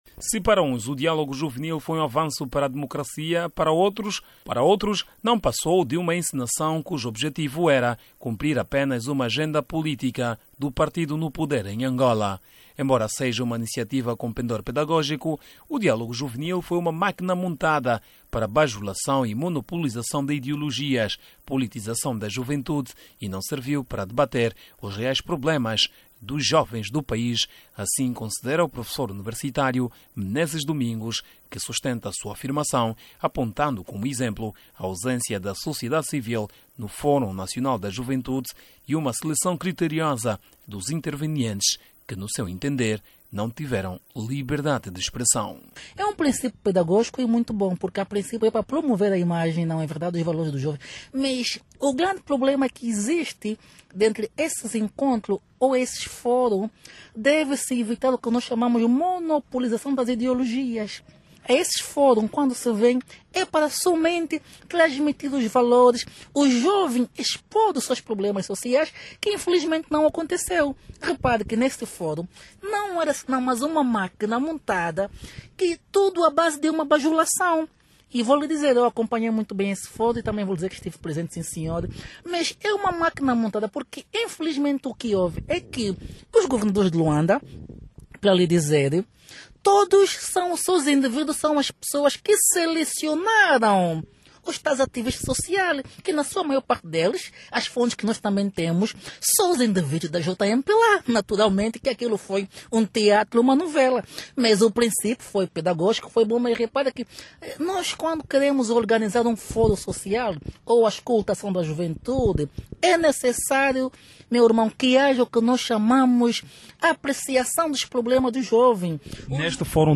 Debate juvenil causa controvérsia